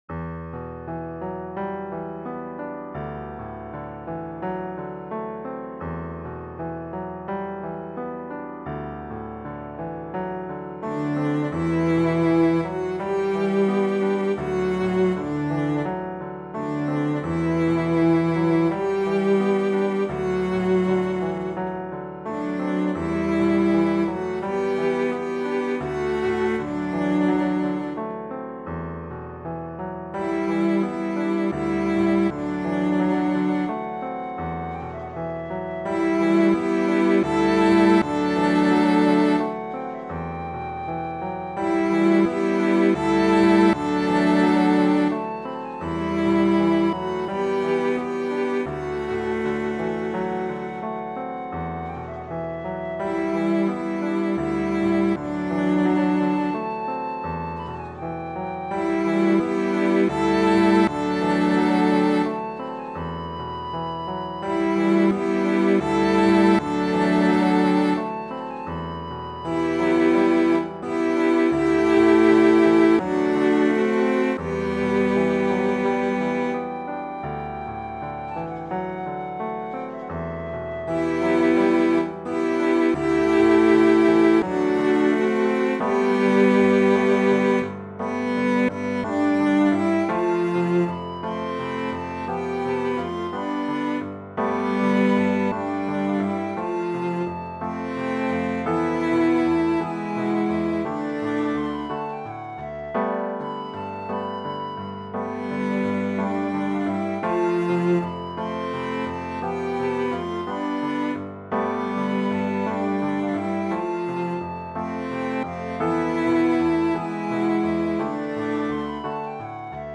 Voicing/Instrumentation: SATB , Flute Solo